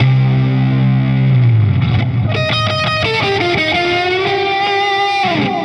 Index of /musicradar/80s-heat-samples/85bpm